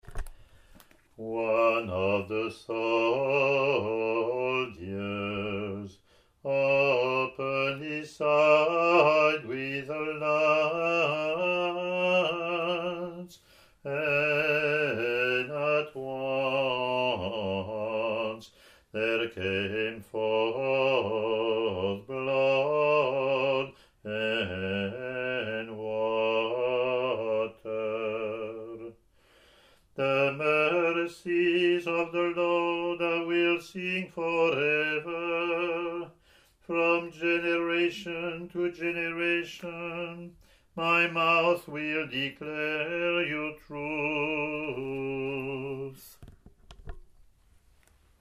English antiphon – English verseLatin antiphon + verses)